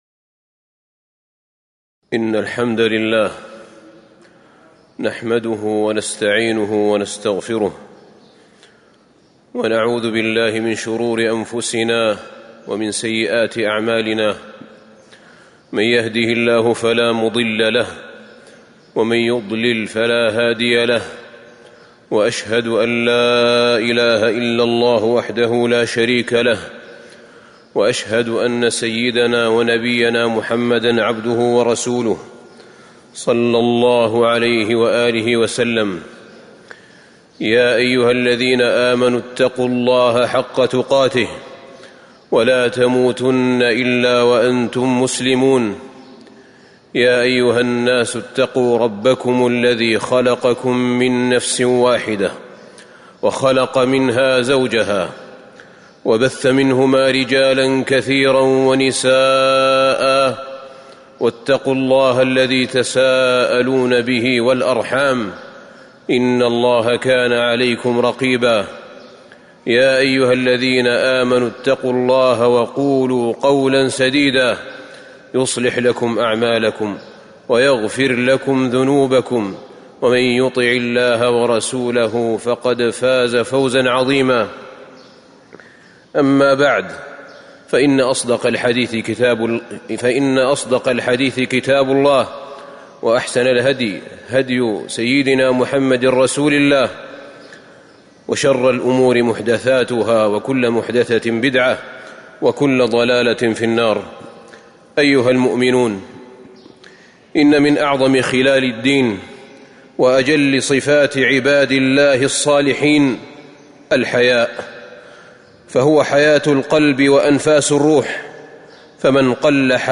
تاريخ النشر ٢١ محرم ١٤٤٤ هـ المكان: المسجد النبوي الشيخ: فضيلة الشيخ أحمد بن طالب بن حميد فضيلة الشيخ أحمد بن طالب بن حميد لحاء الحياء